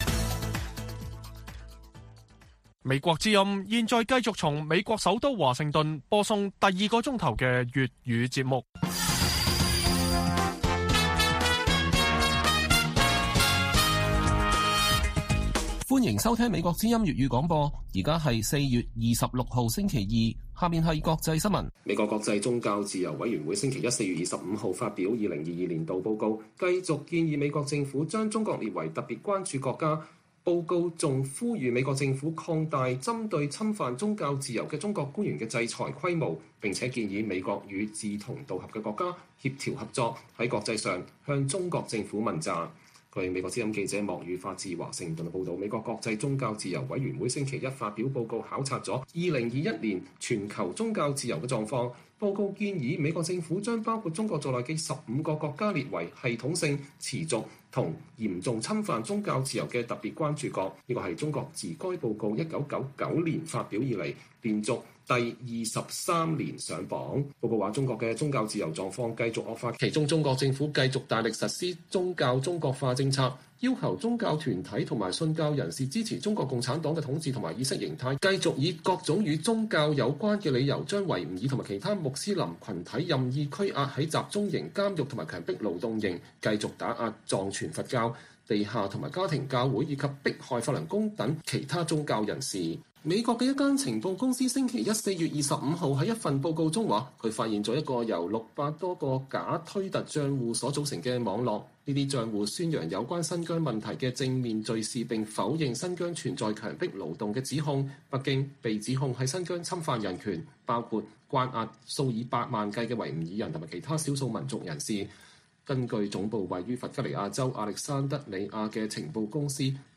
粵語新聞 晚上10-11點: 中國官方急刪《四月之聲》 點燃網民怒火